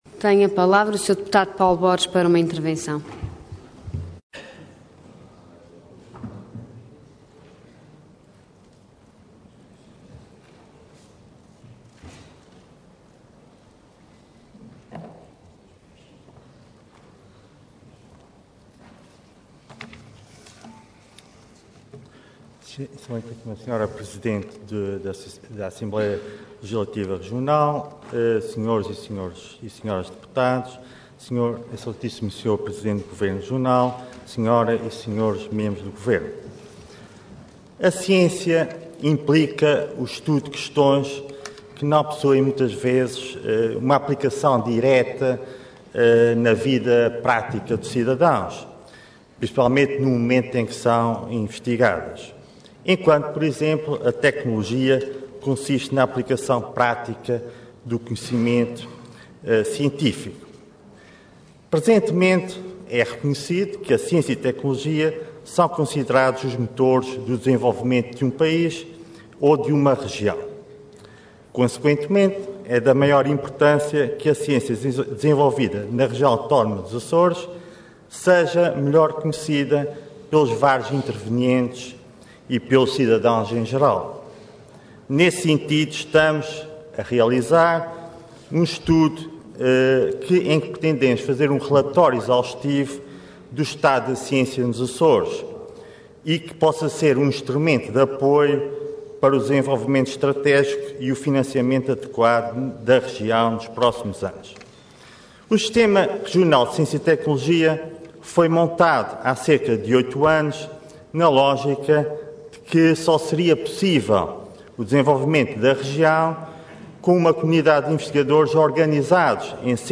Detalhe de vídeo 20 de março de 2013 Download áudio Download vídeo Diário da Sessão Processo X Legislatura Plano e Orçamento 2013 - Ciência nos Açores Intervenção Intervenção de Tribuna Orador Paulo Borges Cargo Deputado Entidade PS